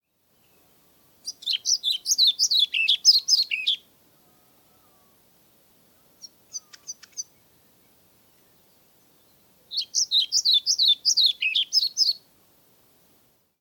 Svarthvit fluesnapper (Ficedula hypoleuca)
Engelsk navn: Pied Flycatcher